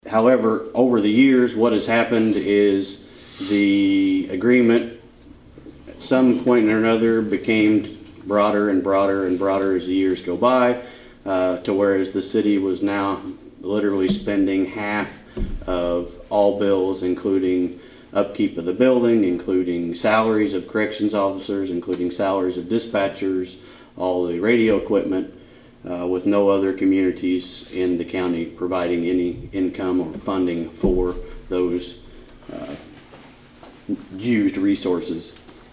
Carter Gives Update on new Carmi PD Building at Kiwanis Meeting
Carter gave an update on the new Carmi Police Department, the old Leggett’s Market building, at the Thursday Kiwanis meeting.